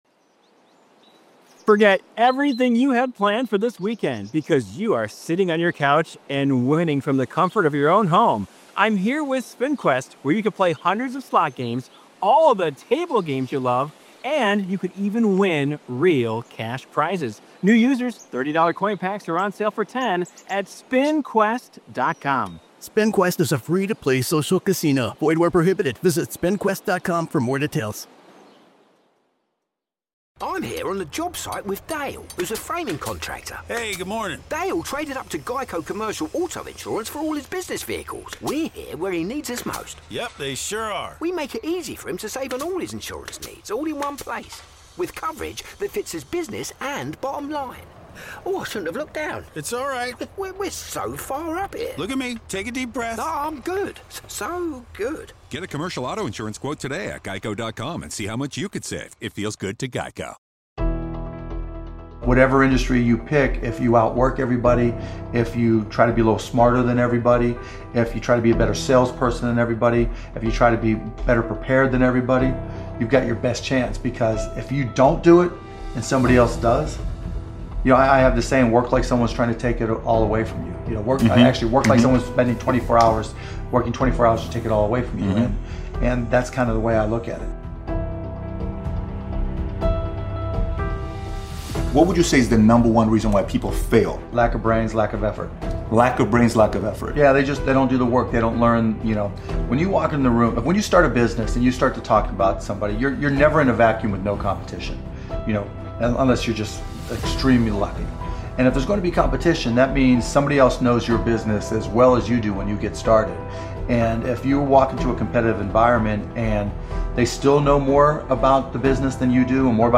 Headliner Embed Embed code See more options Share Facebook X Subscribe Speaker Mark Cuban Mark Cuban is an American businessman and investor with a net worth of over 4 billion dollars.